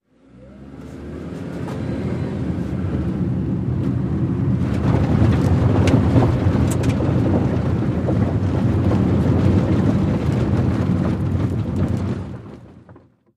Compact Car Vehicle Accelerates To Medium Speed On Pavement, Transitions To Gravel-mix